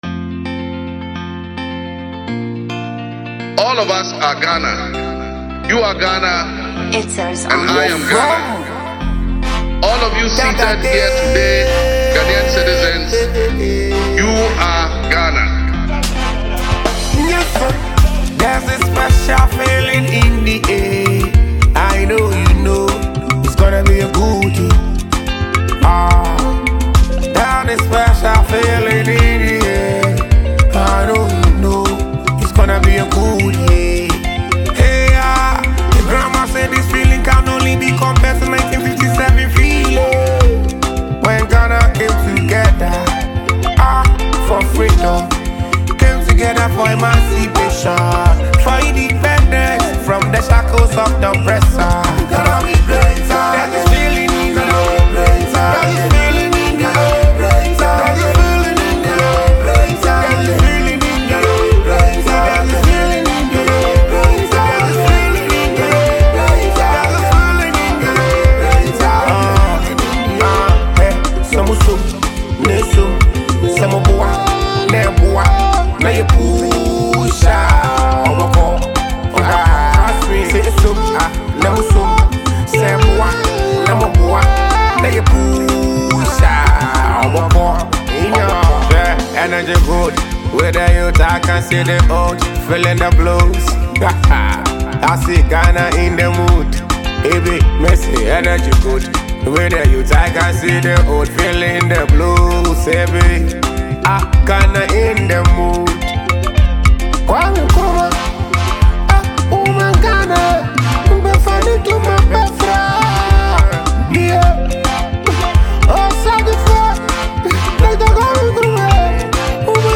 a Ghanaian artist
This is a banger all day.